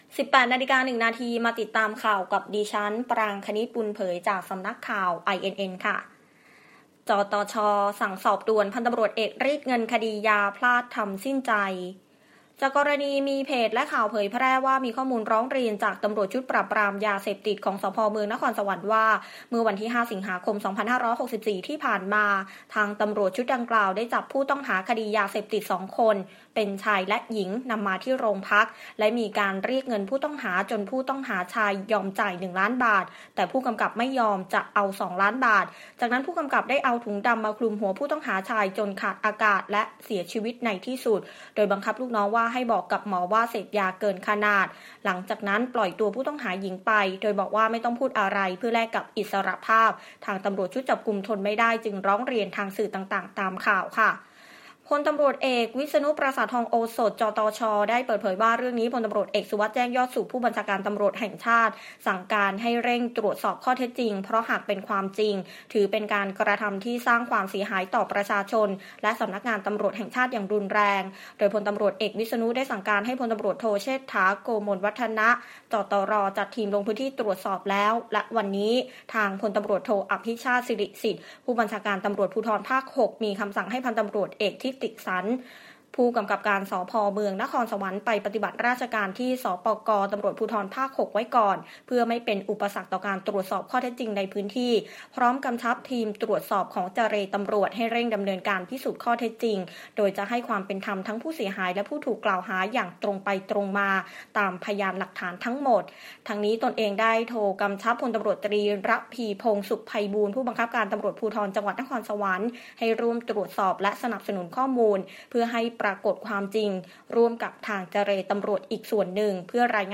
คลิปข่าวต้นชั่วโมง ข่าว